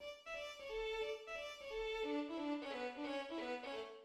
3rd theme (D major)